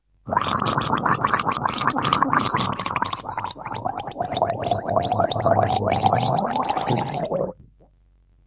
Tag: FX 噪音 震动 振动筛